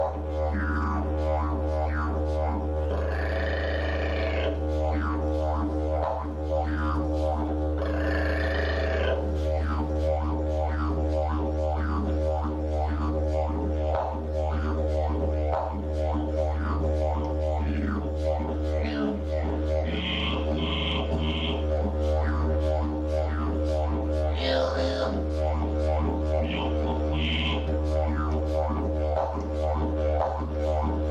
Звуки диджериду
Погрузитесь в мир аутентичных звуков диджериду — древнего духового инструмента аборигенов Австралии.